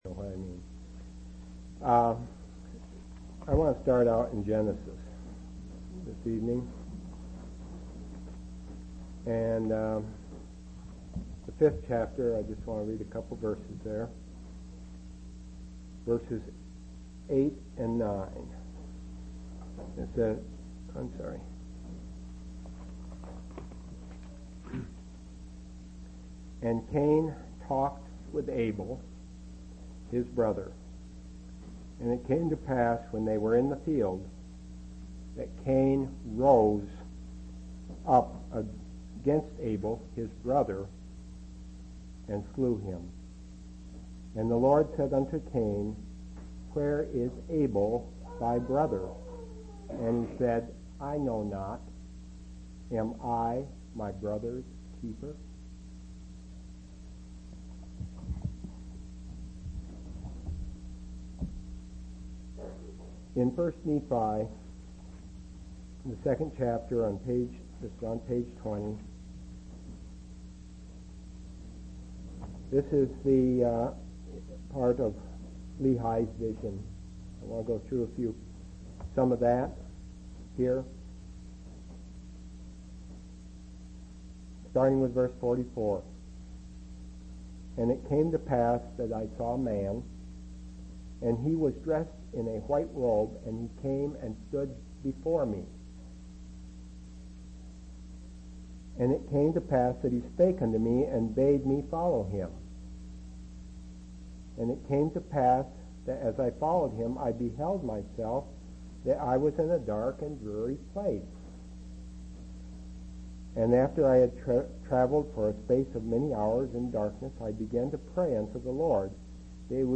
1/31/1999 Location: East Independence Local Event